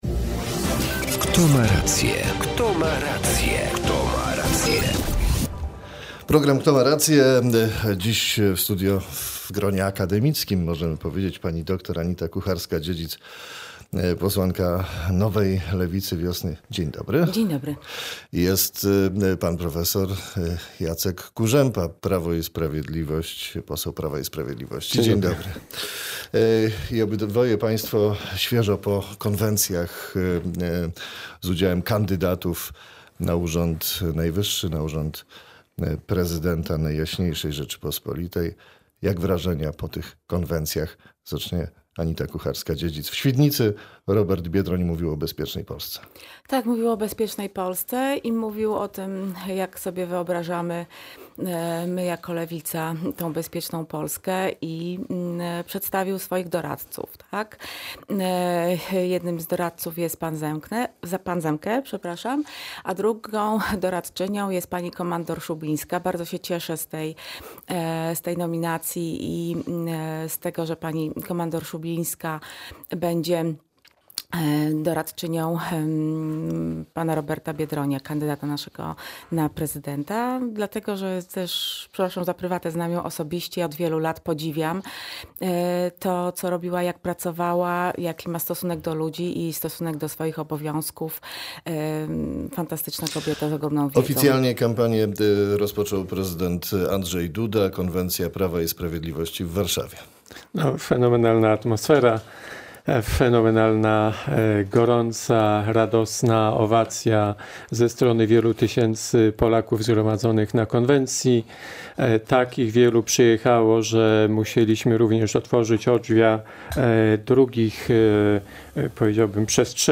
Dziś w rozmowie spotykają posłowie: Anita Kucharska-Dziedzic (Lewica) i Jacek Kurzępa (PiS).